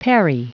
Prononciation du mot parry en anglais (fichier audio)
Prononciation du mot : parry